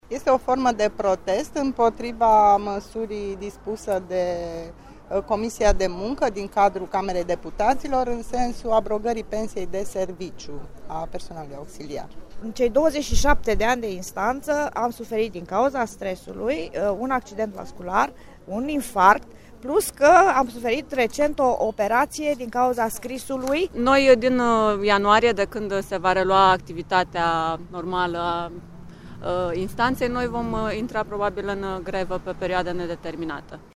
La Braşov au protestat aproape 100 de persoane, care au explicat şi motivele pentru care au decis să iasă în stradă.
GREFIERI-VOXURI.mp3